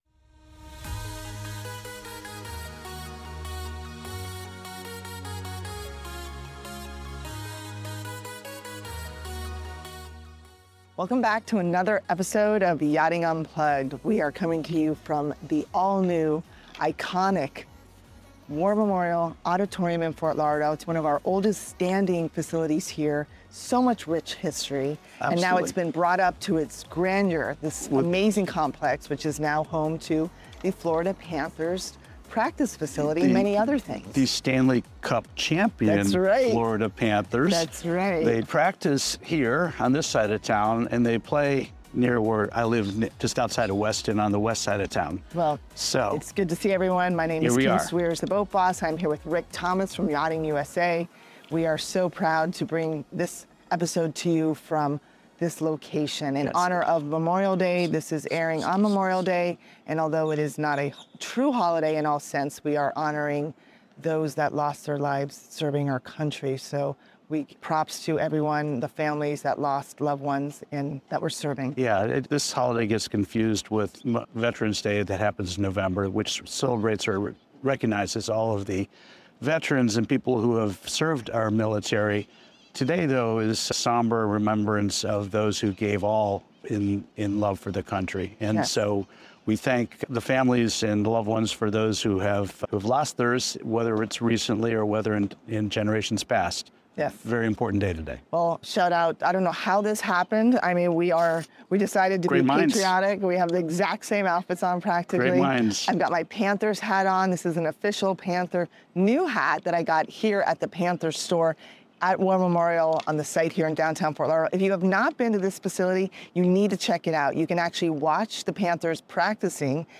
from the newly renovated War Memorial Auditorium in Fort Lauderdale